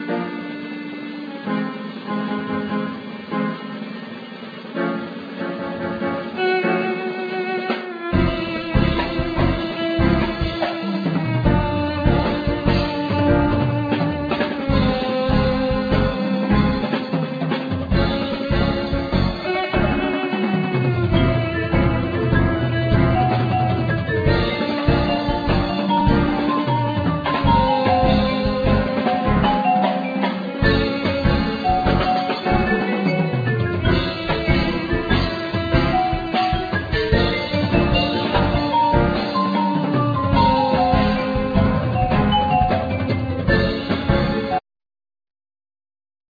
Accordeon,Vocal
Violin,Viola
Keyboards,Vocals
Electric & Acoustic Bass,Guiro
Vibraphone,Marimba,Glockenspiel,Percussion
Drums,Percussion